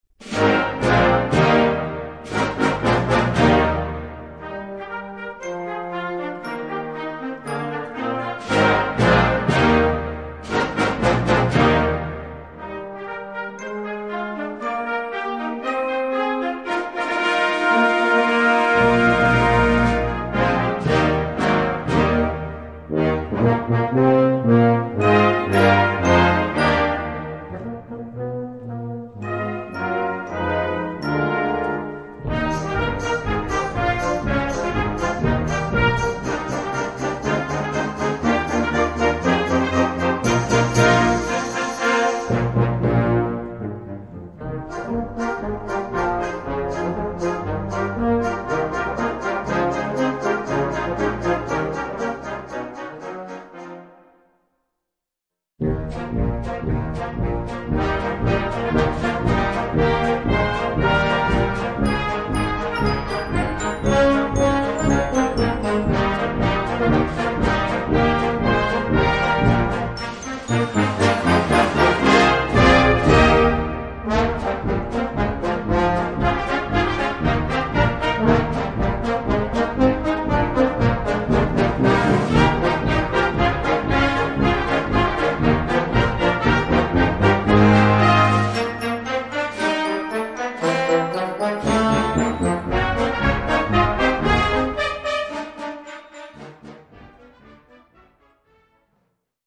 Besetzung: Blasorchester
Für fünfstimmig variable Besetzung.